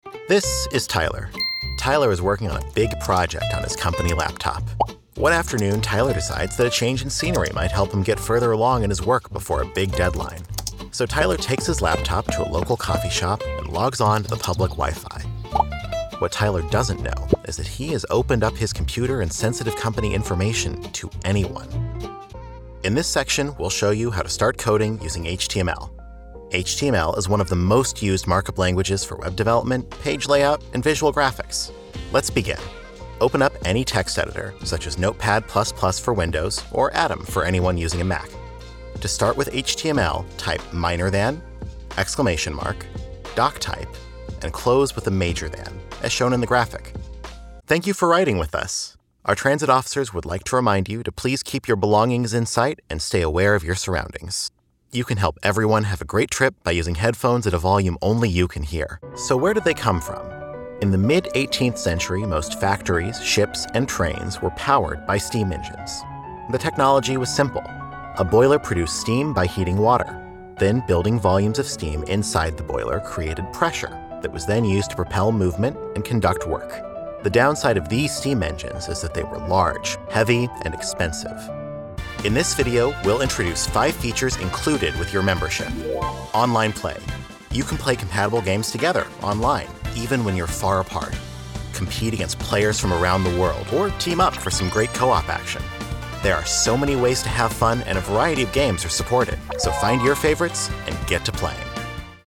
a warm and knowledgable millennial
Narration